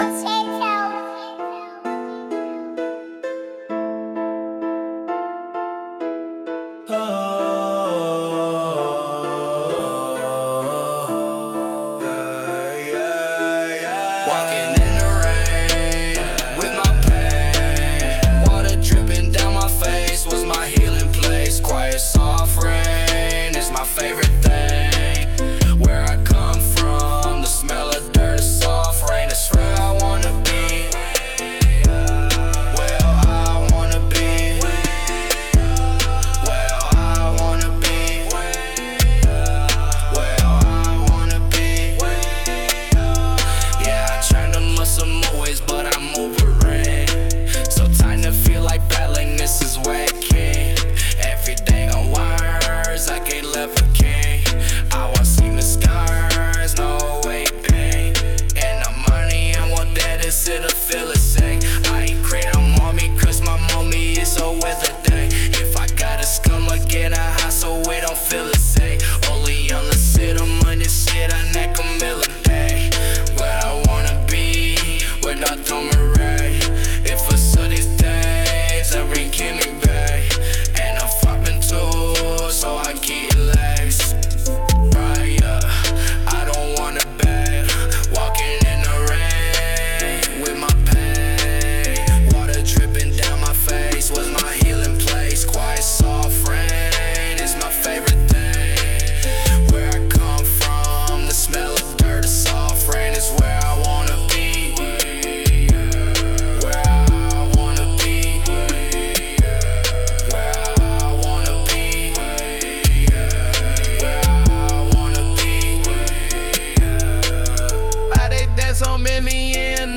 An emotional Southern hip-hop and trap anthem